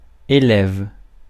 Ääntäminen
IPA: [e.lɛv]